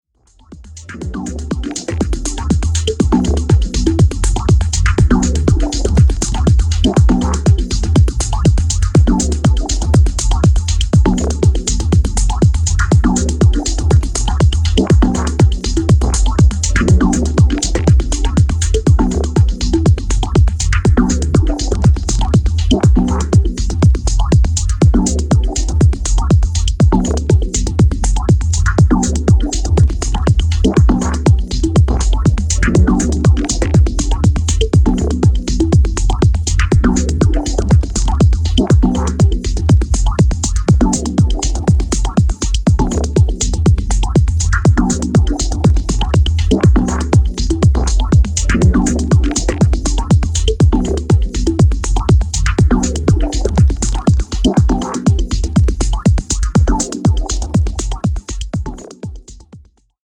Deep House Dub Techno House